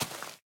snapshot / assets / minecraft / sounds / dig / grass4.ogg
grass4.ogg